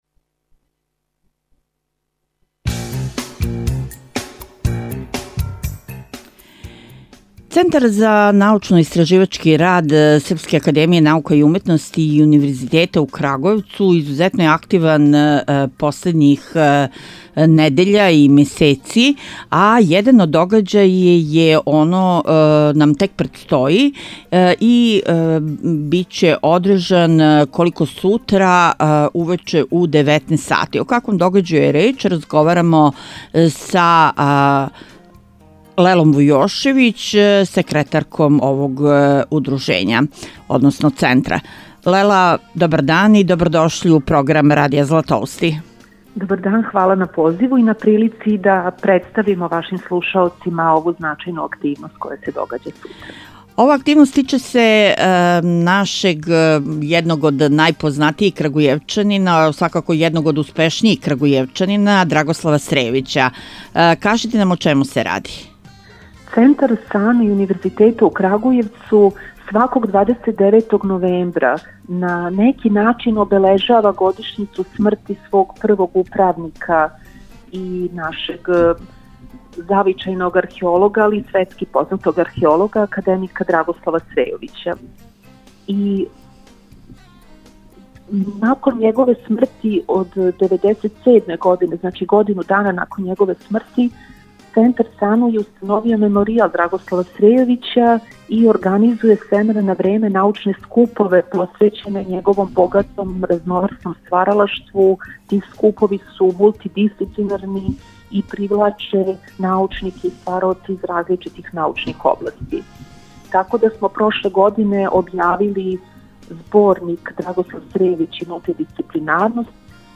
Више у разговору